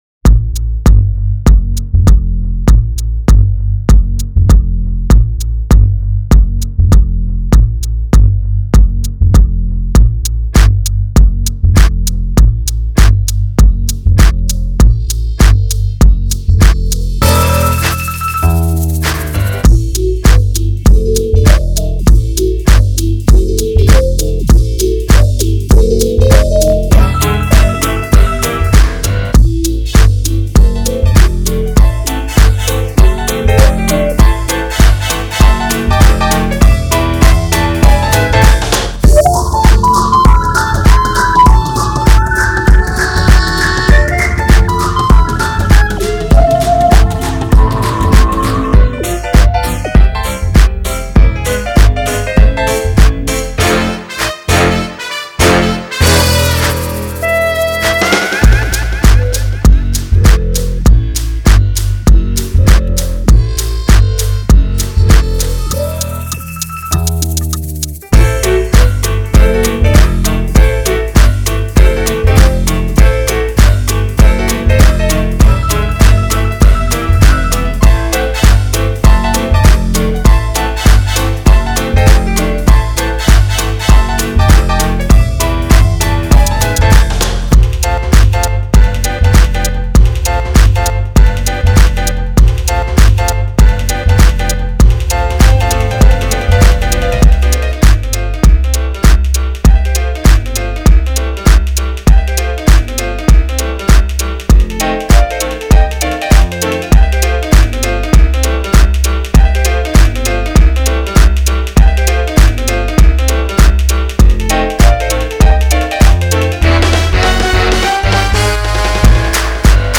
A little jazzy =)
I label this Progressive Funk Jazz.